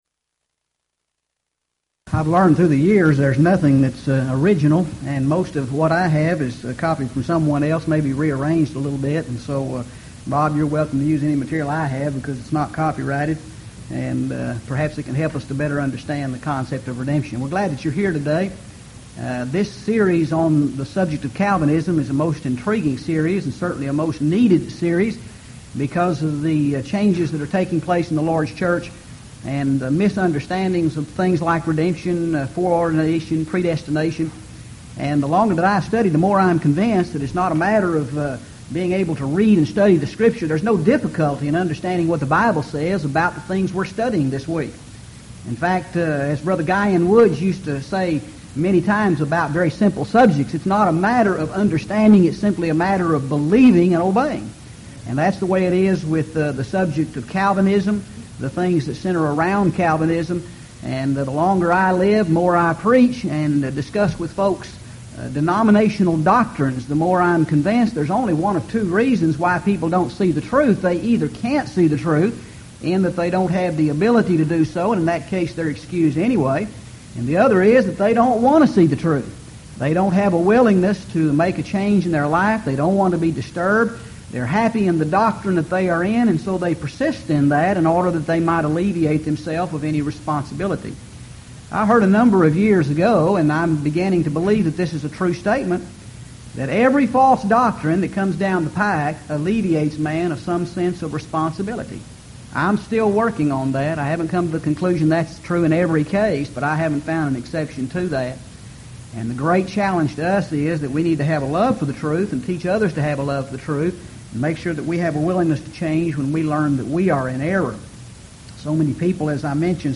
Event: 1998 Houston College of the Bible Lectures Theme/Title: Calvinism
lecture